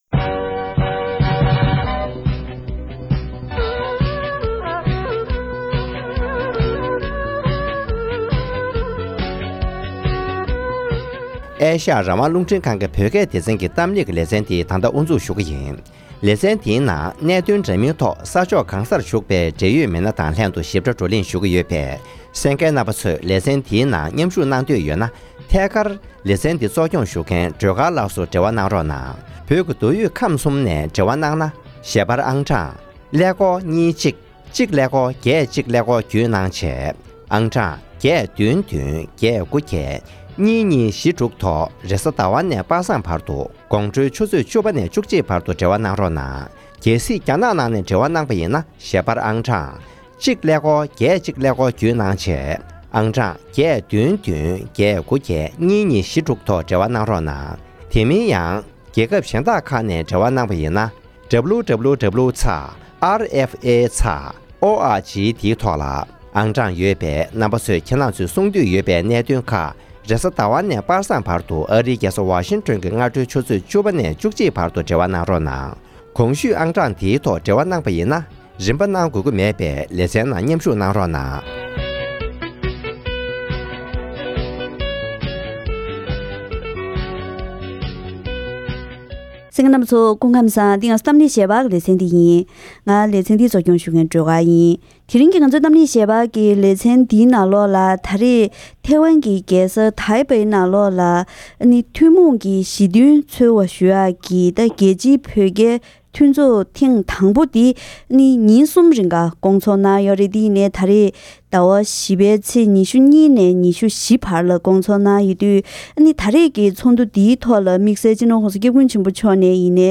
༄༅། །ཐེངས་འདིའི་གཏམ་གླེང་ཞལ་པར་གྱི་ལེ་ཚན་ནང་དུ།
རྒྱལ་སྤྱིའི་བོད་རྒྱའི་ཚོགས་འདུའི་ཐོག་བོད་རྒྱའི་གནད་དོན་སེལ་ཐབས་ཐད་བགྲོ་གླེང་ཇི་འདྲ་བྱུང་མིན་ཐོག་འབྲེལ་ཡོད་དང་བཀའ་མོལ་ཞུས་པར་གསན་རོགས་ཞུ།།